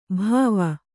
♪ bhāva